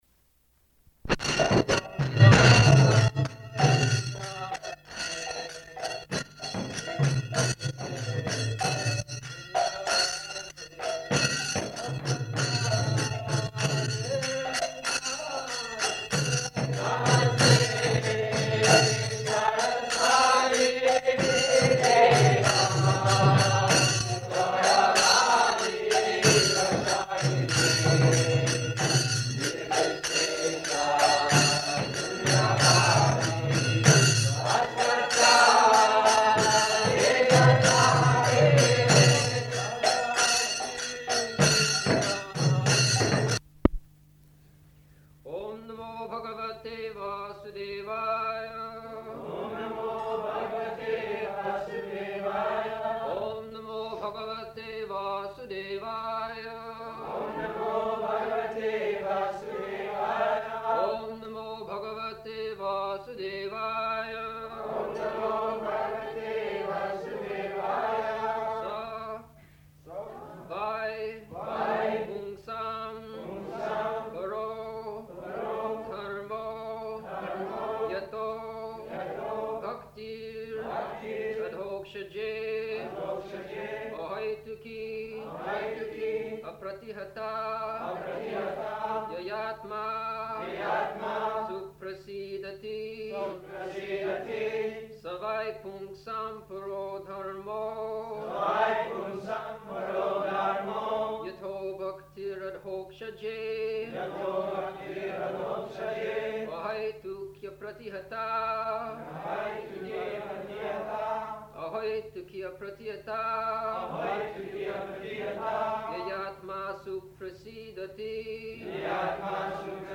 May 24th 1974 Location: Rome Audio file: 740524SB.ROM.mp3 [ kīrtana ] [poor recording]
[devotees repeat]